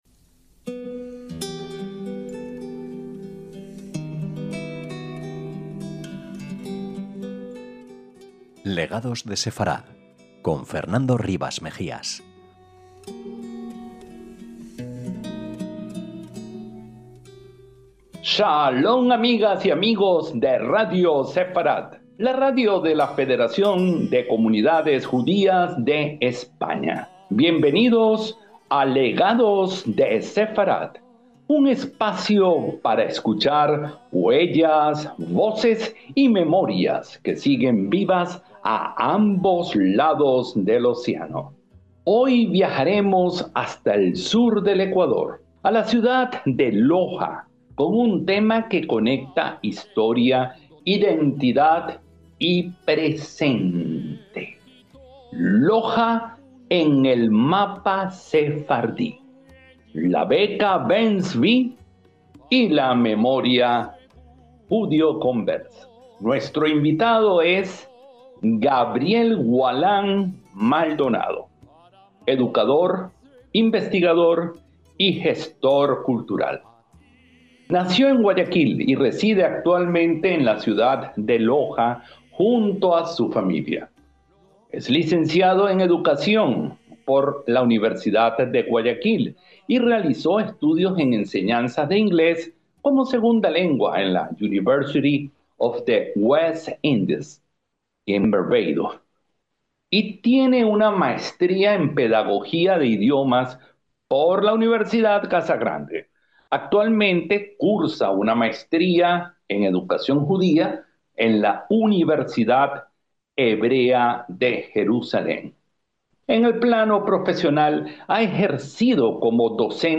educador, investigador y gestor cultural. Junto a él, proponemos una idea central: la identidad judeoconversa no como un capítulo cerrado del pasado, sino como memoria viva: en relatos familiares, prácticas culturales, preguntas heredadas, búsquedas personales y formas de nombrarse.